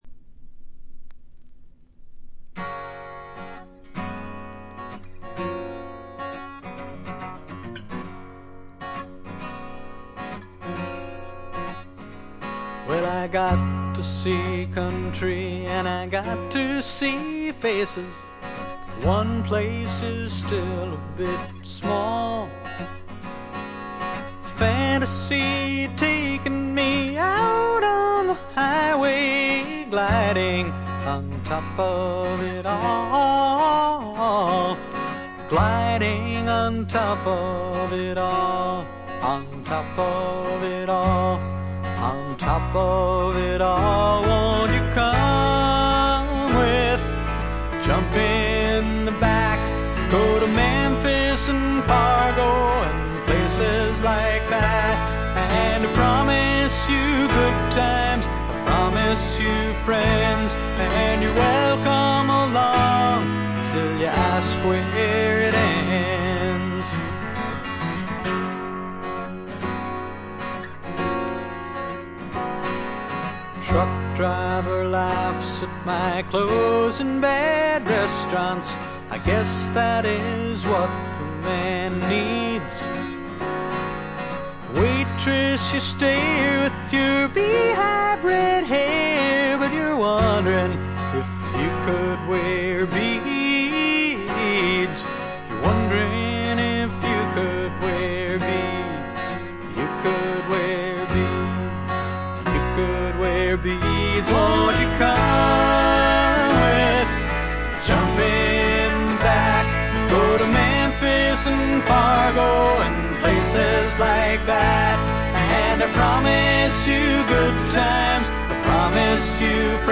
*** LP ： USA 1972
ほとんどノイズ感無し。
試聴曲は現品からの取り込み音源です。